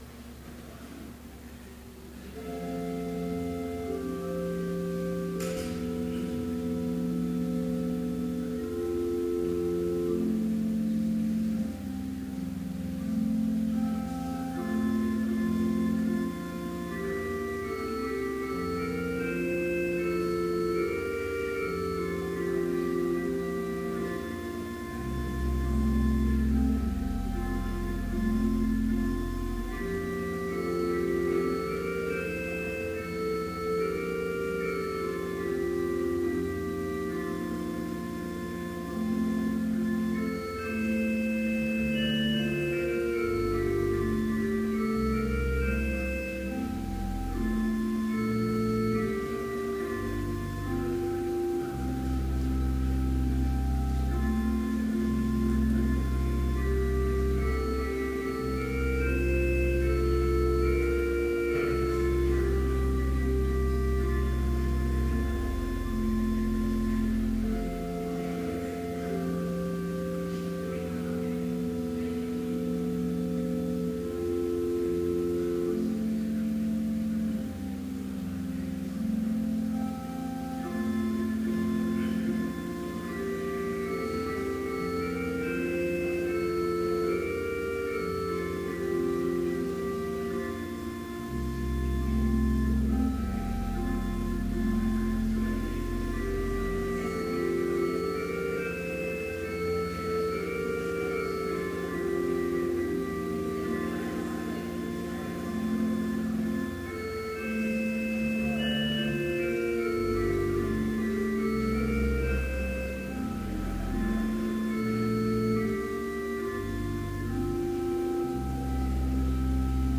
Complete service audio for Chapel - December 18, 2014